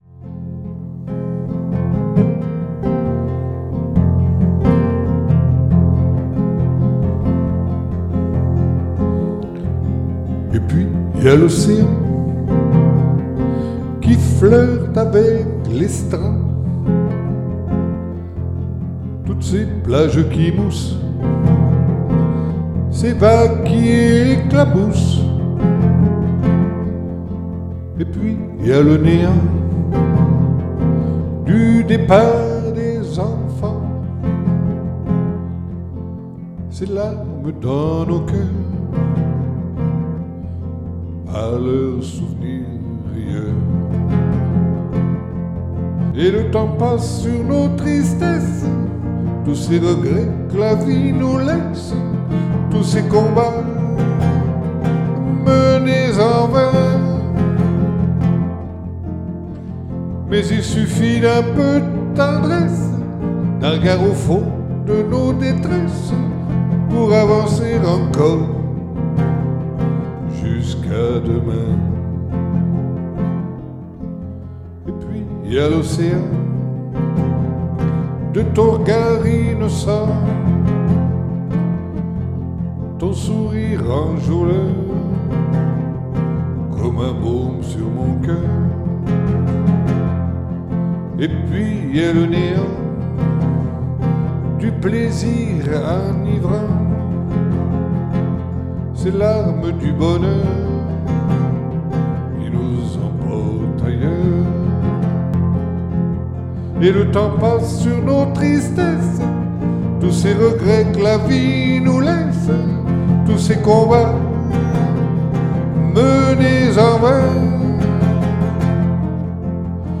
[Capo 2°]